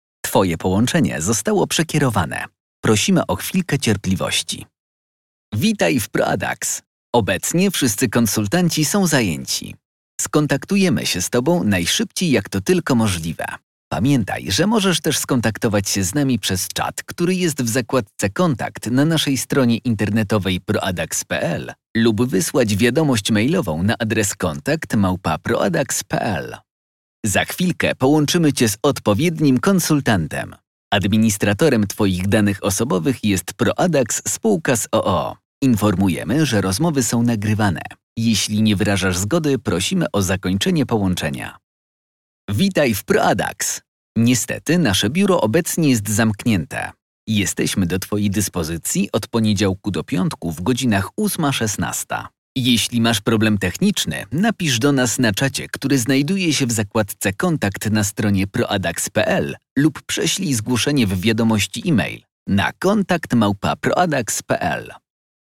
Komunikaty IVR 2025 – Centrale telefoniczne
demo_PROADAX_IVR_2025.mp3